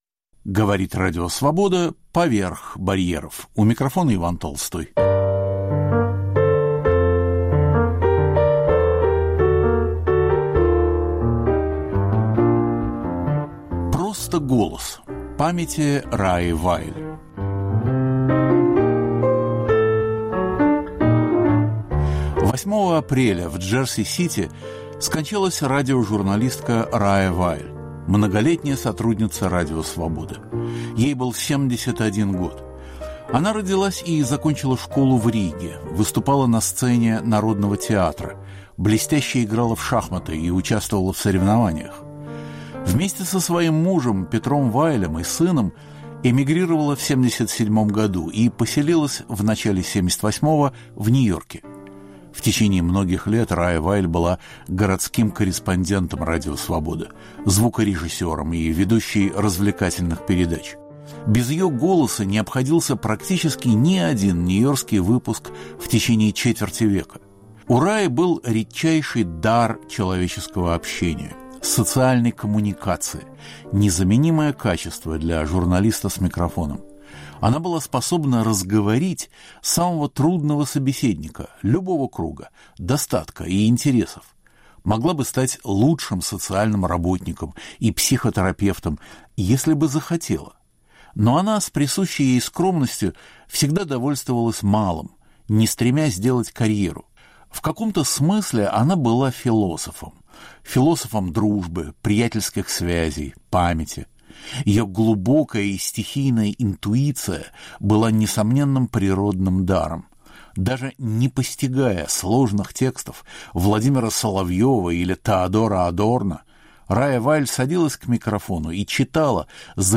Мемориальная программа составлена из фрагментов передач разных лет и названа - "Просто голос".